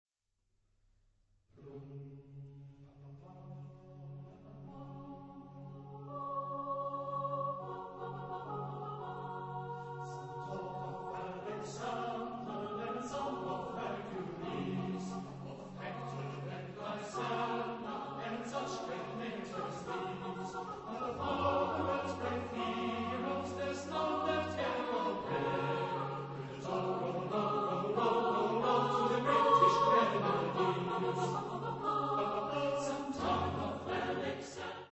Type de choeur : SATBarB